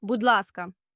bood' LAHS-kah you're welcome / please